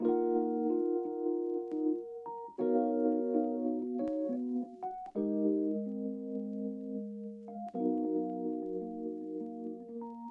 罗德钢琴从爵士乐的心情循环播放
Tag: 75 bpm Jazz Loops Piano Loops 2.15 MB wav Key : D